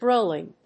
音節grówl・ing 発音記号・読み方
/ˈgrolɪŋ(米国英語), ˈgrəʊlɪŋ(英国英語)/